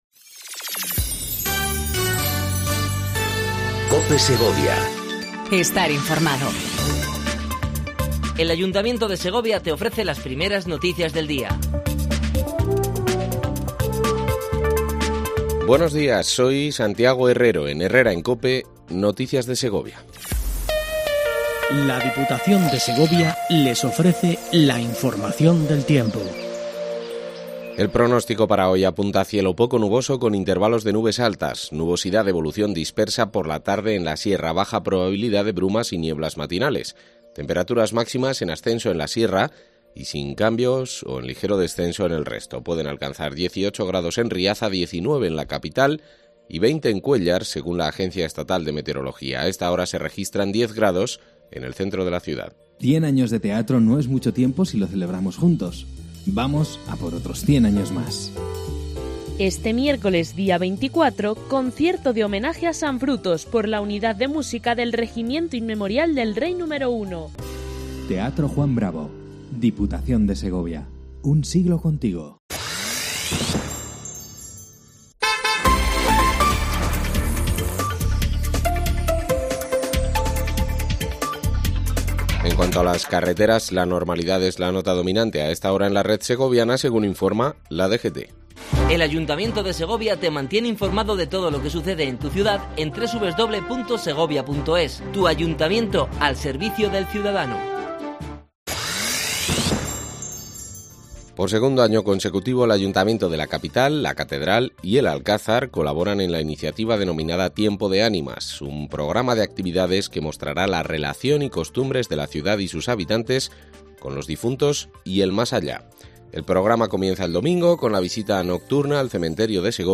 AUDIO: Primer informativo local en cope segovia